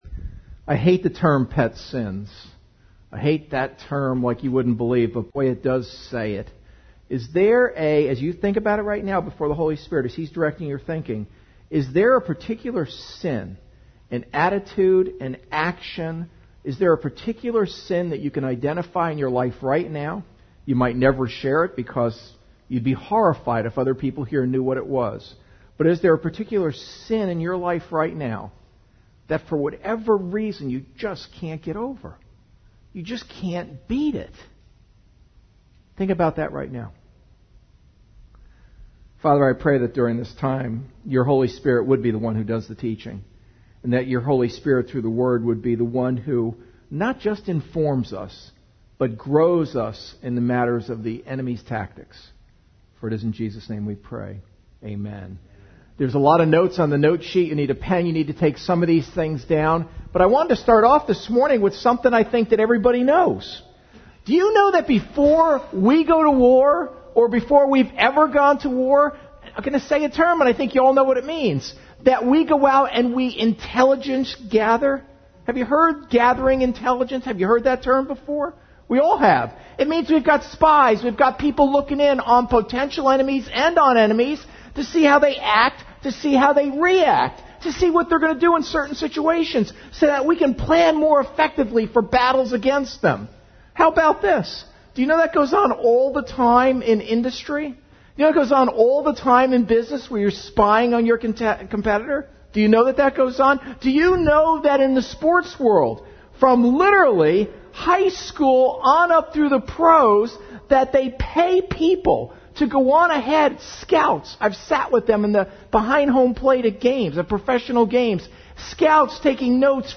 Understand the enemy's tactics (Part 1) - Candlewood Community Church